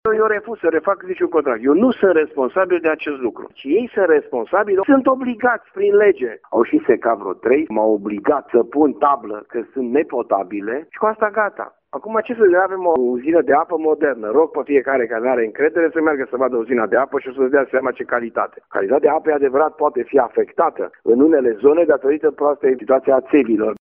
Primarul Dorin Florea spune că sarcina de a monitoriza calitatea apei izvoarelor aparține DSP-ului, nu Primăriei.